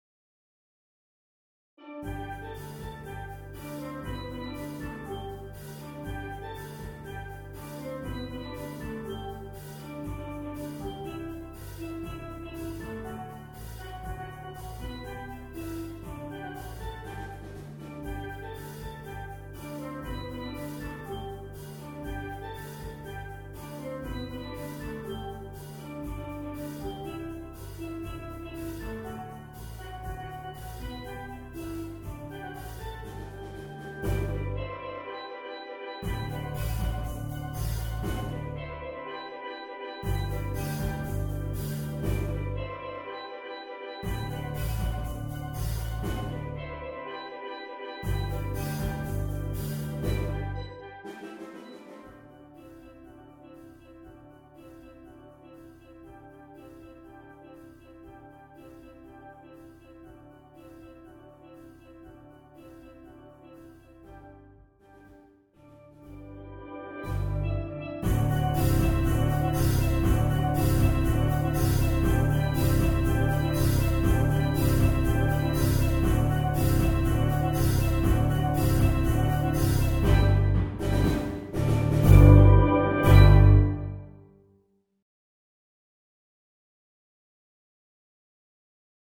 Voicing: Steel Drum